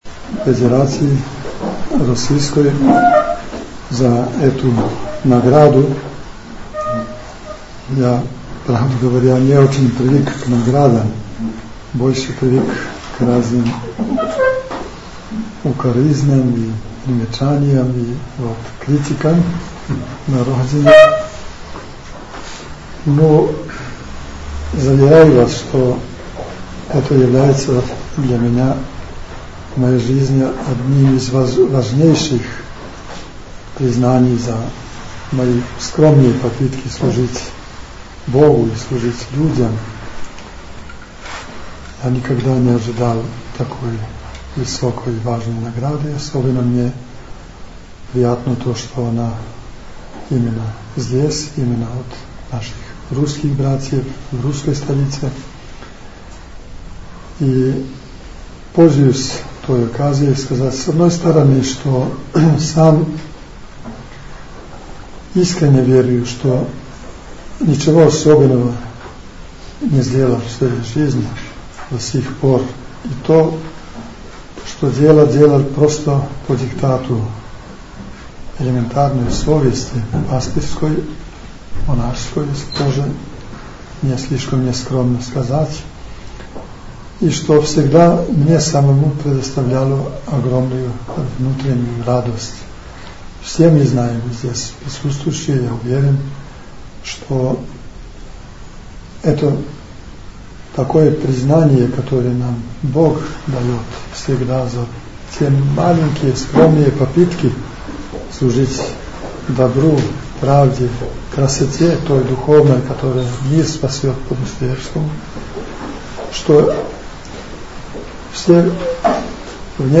Звучни запис са доделе одликовања